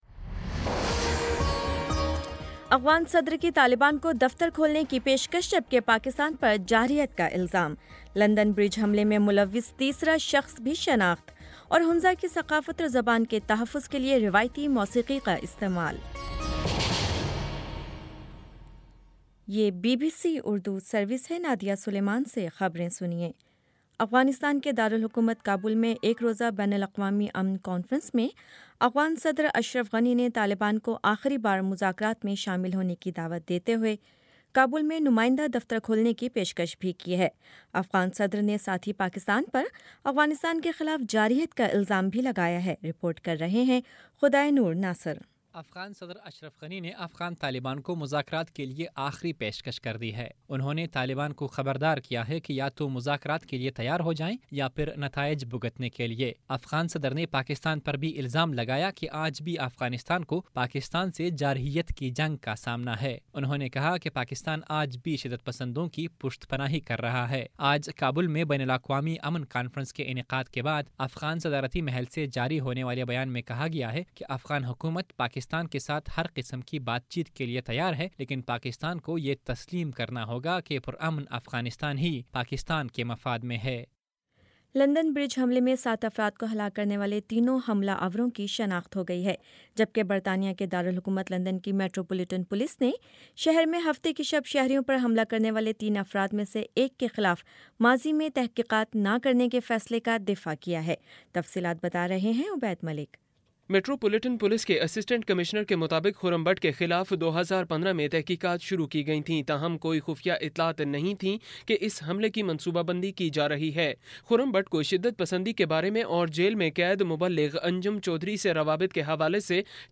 جون 06 : شام سات بجے کا نیوز بُلیٹن